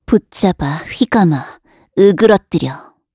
Gremory-Vox_Skill3_kr.wav